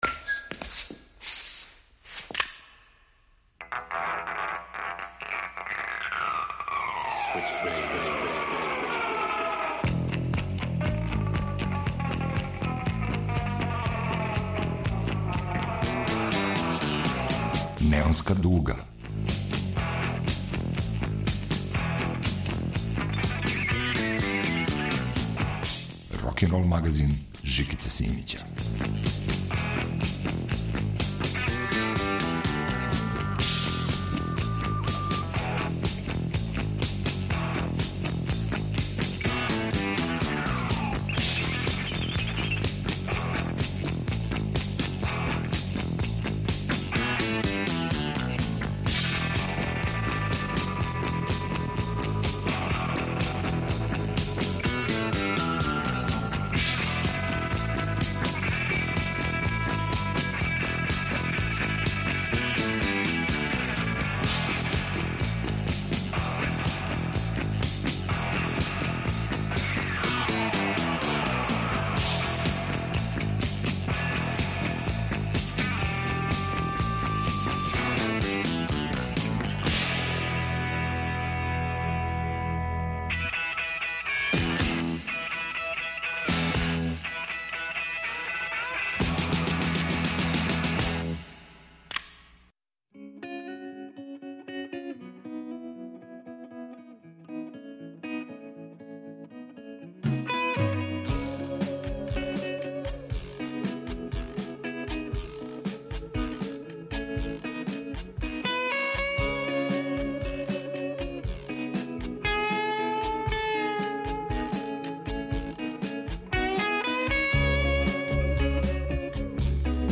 Преко двадесет одабраних песама су извештаји са те авантуре.
Рокенрол као музички скор за живот на дивљој страни. Вратоломни сурф кроз време и жанрове.